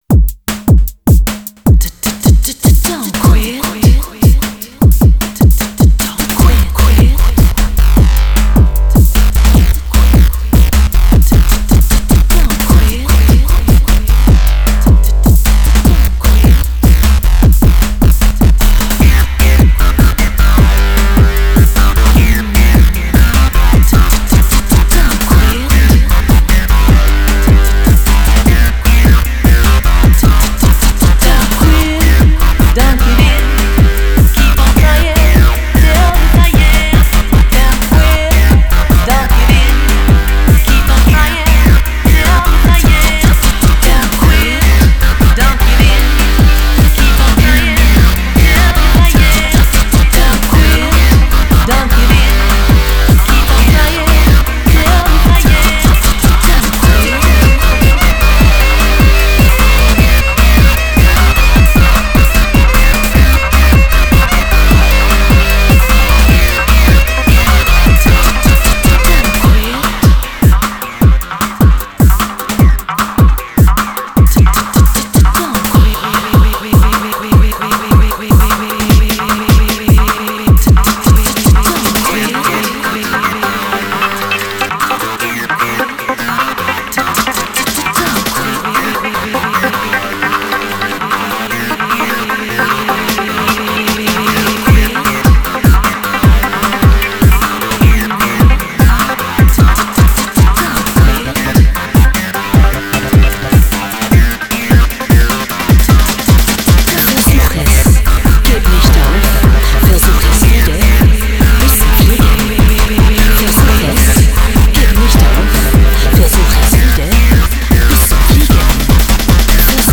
solo dance CD